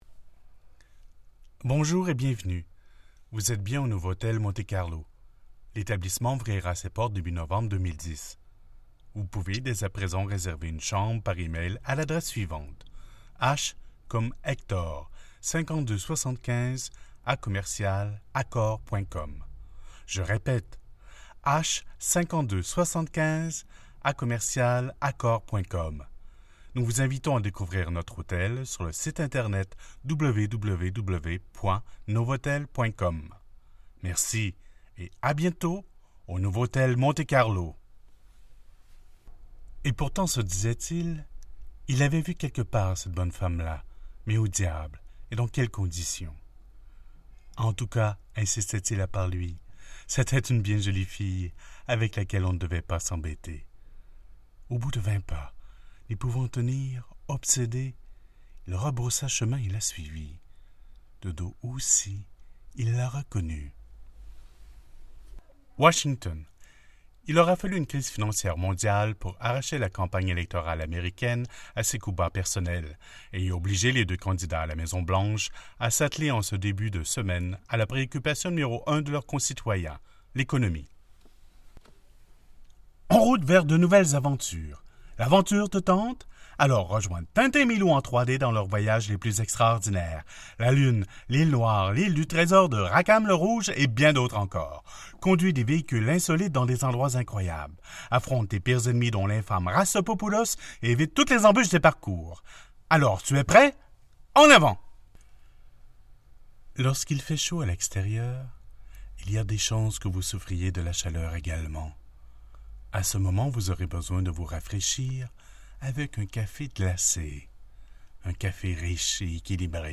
Man
For Commecials: Ranges from sexy and seductive to fun and upbeat, to friendly, assuring guy next door.
kanadisch-fr
Sprechprobe: Werbung (Muttersprache):